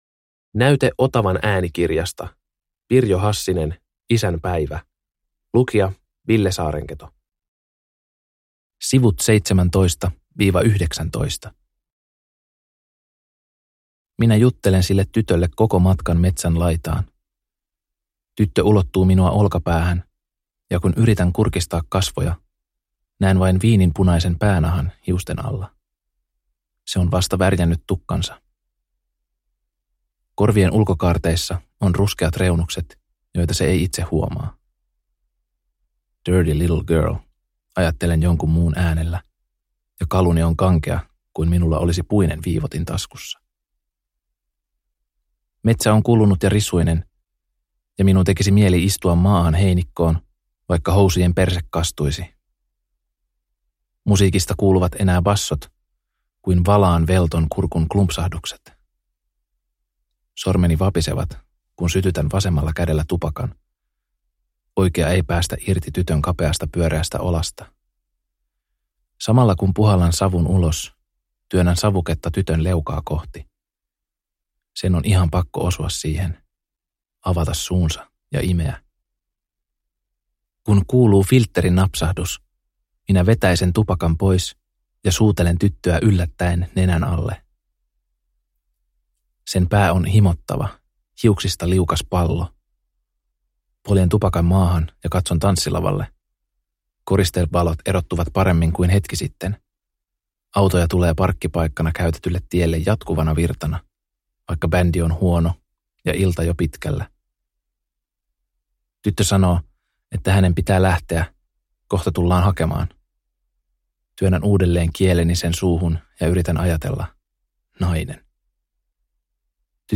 Isänpäivä – Ljudbok – Laddas ner